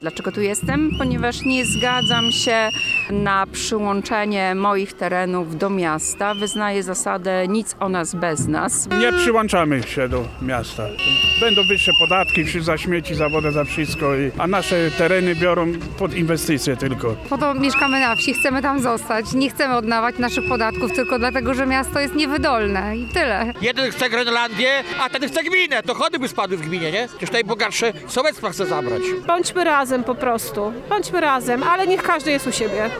Na miejscu był nasz reporter.